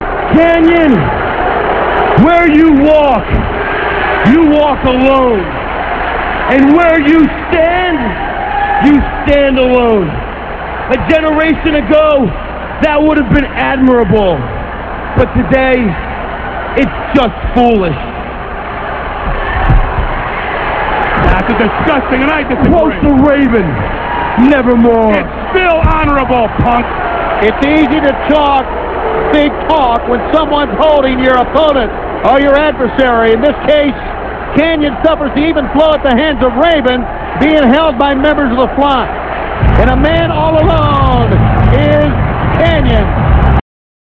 - This speech comes from Nitro - [6.29.98]. He jumps Kanyon and tells him that he's a fool for standing alone.